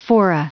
Prononciation du mot fora en anglais (fichier audio)
Prononciation du mot : fora